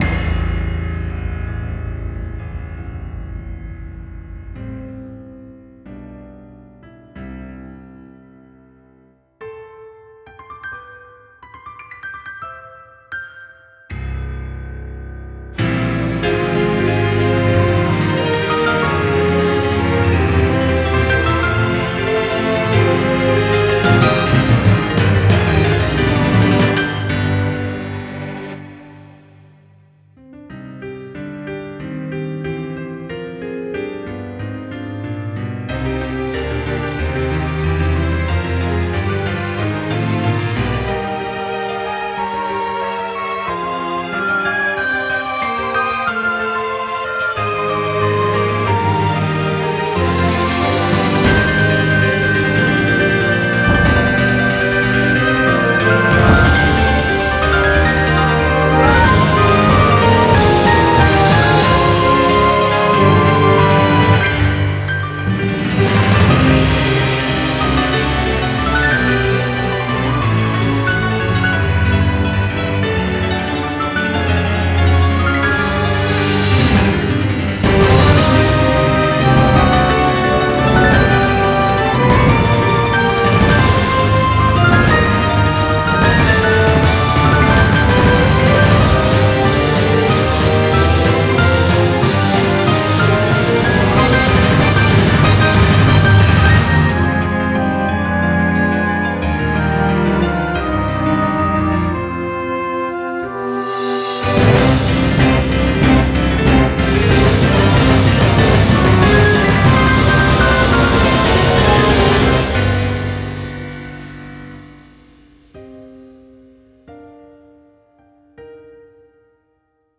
BPM45-188
This is a dirge.